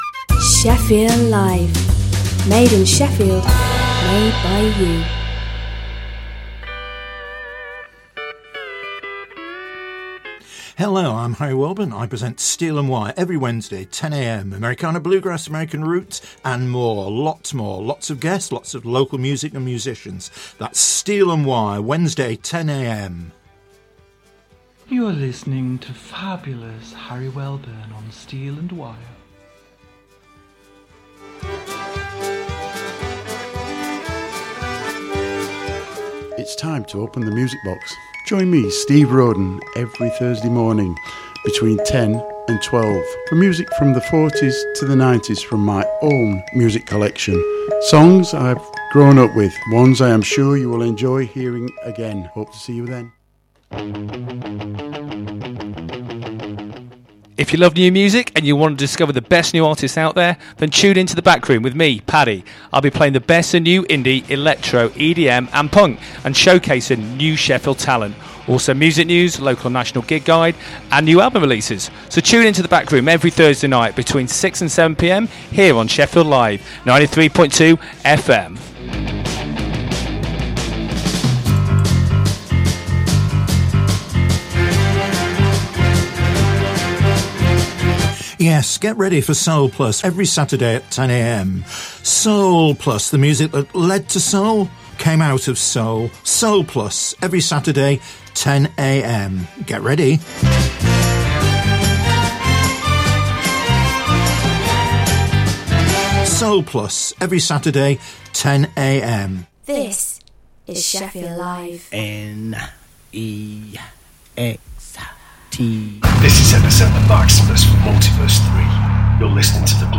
2 hours of the best popular classic and Prog rock music plus Gig and band info..